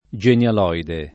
genialoide [ J en L al 0 ide ] s. m. e f.